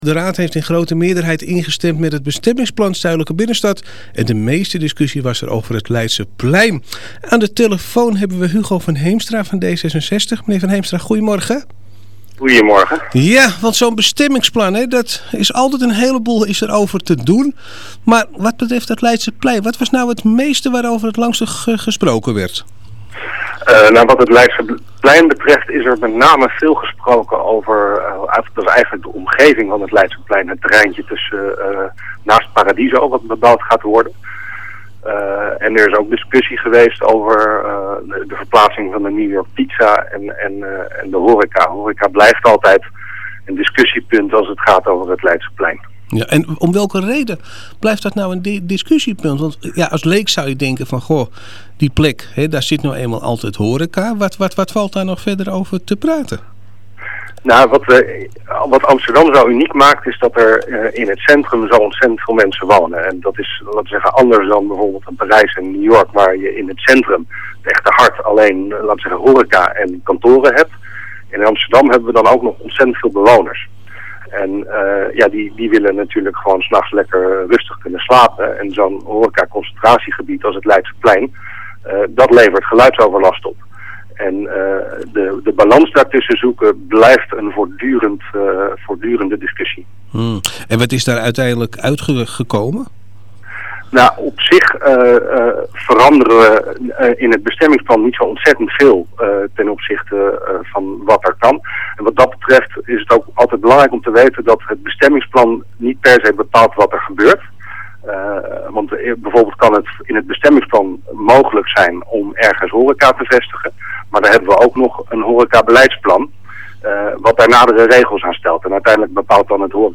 D66-raadslid Hugo van Heemstra vertelt hier meer over.